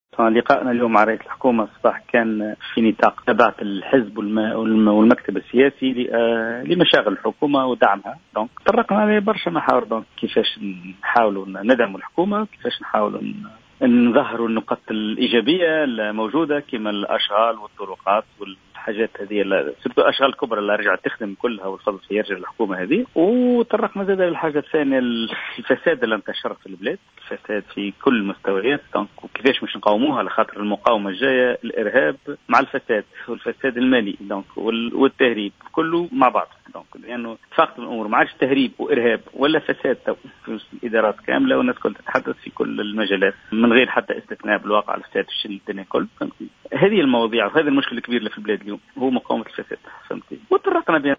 Le député Afek Tounes à l'ARP, Hafedh Zouari, est revenu, lors d'une déclaration accordée à Jawhara FM ce dimanche 18 octobre 2015, sur les principaux points discutés lors de la réunion des dirigeants d'Afek Tounes avec le chef du gouvernement Habib Essid.